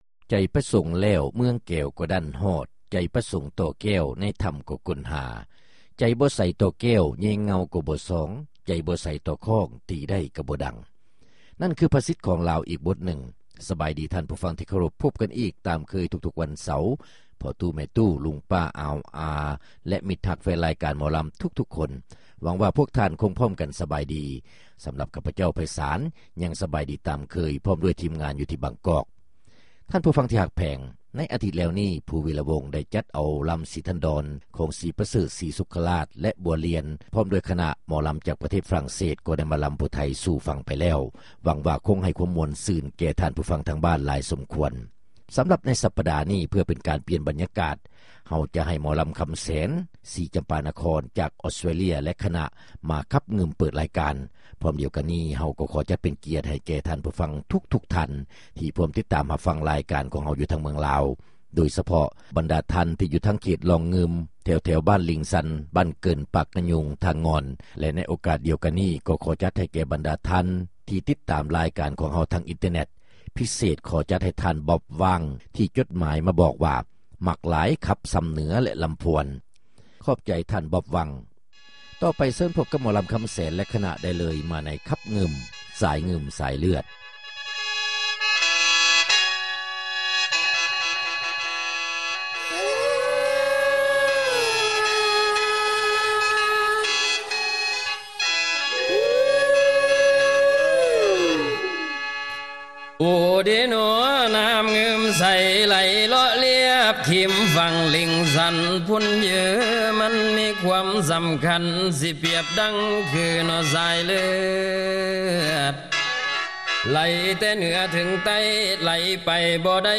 ຣາຍການໜໍລຳ ປະຈຳສັປະດາ ວັນທີ 5 ເດືອນ ສິງຫາ ປີ 2005